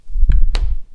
关闭金属门
描述：关闭公寓楼下的金属门
标签： 近距离 公寓 金属 关闭
声道立体声